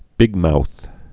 (bĭgmouth)